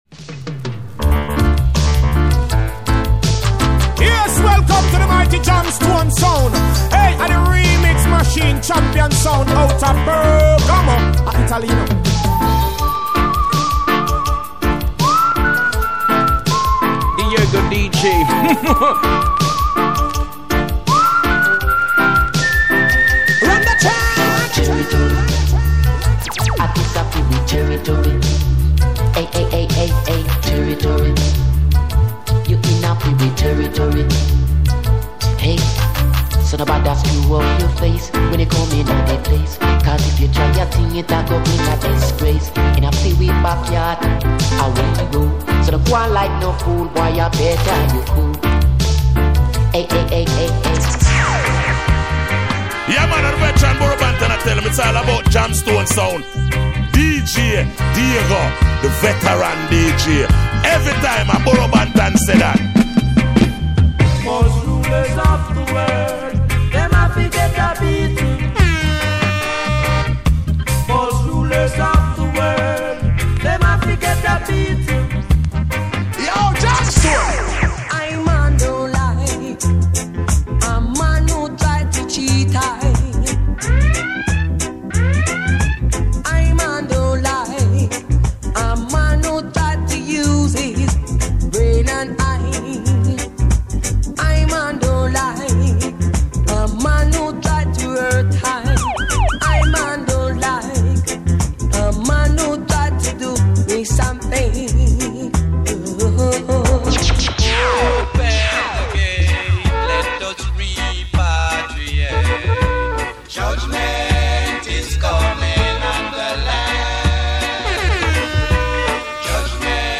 All tracks mixed